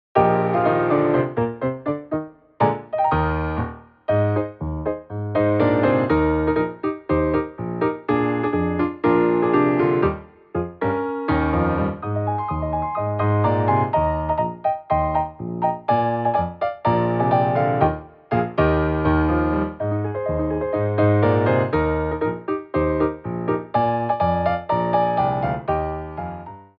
QUICK TEMPO